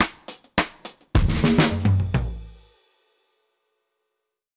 Index of /musicradar/dub-drums-samples/105bpm
Db_DrumsB_Dry_105-04.wav